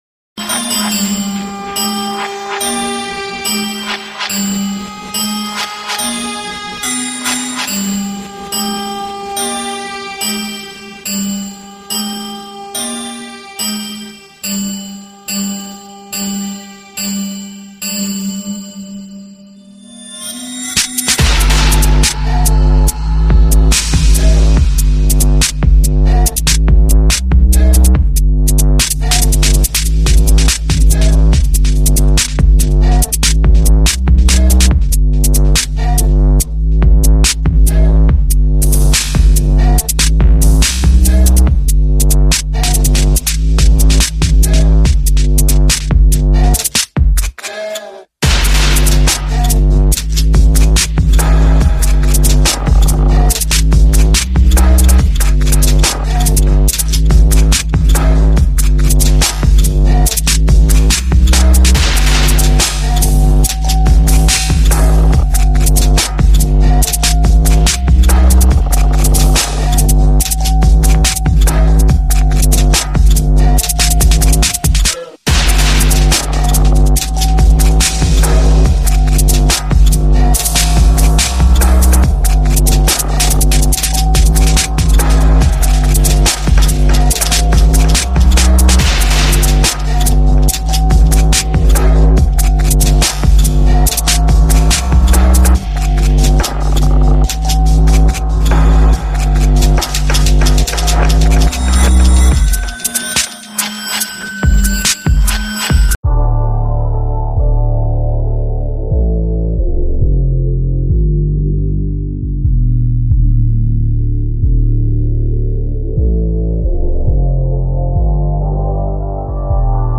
Der Beat ballert schon sehr, auch wenn er etwas übersteuert ist.